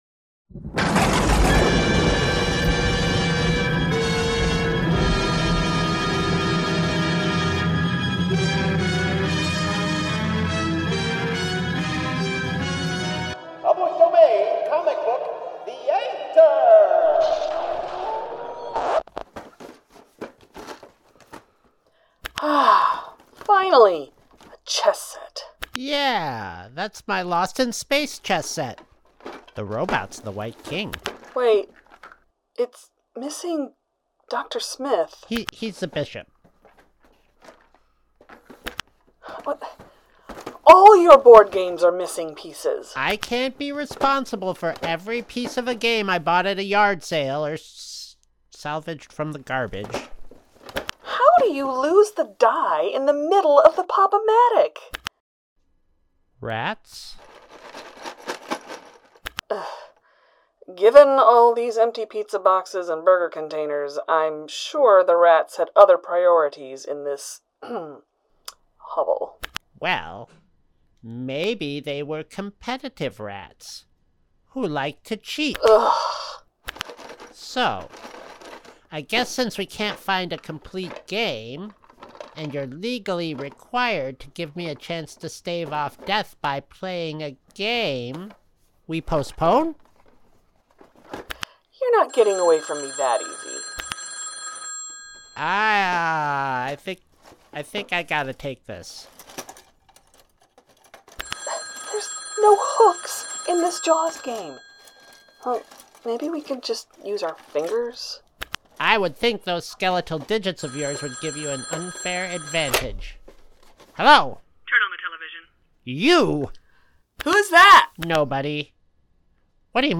The Ocadecagonagon Theater Group